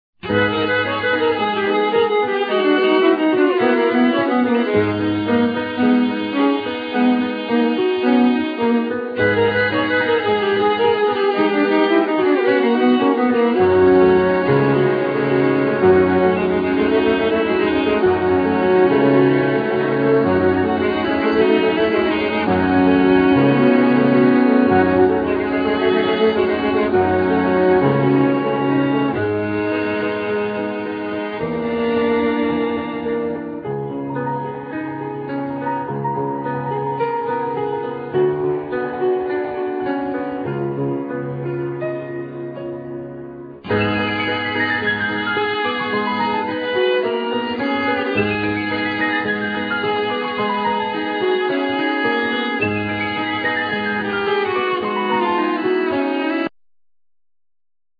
Voices
Viola
Cello
Piano